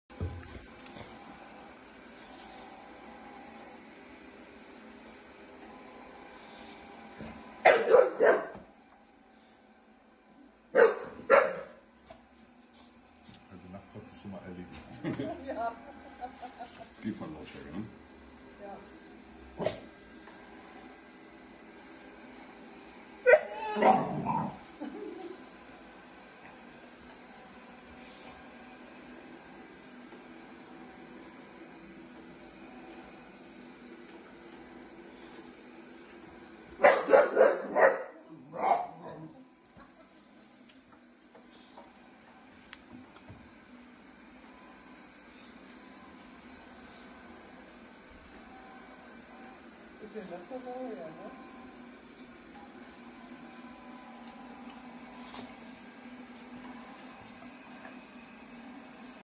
Aber typisch OEB was neues = Angst muss erst mal angebellt werden 😂.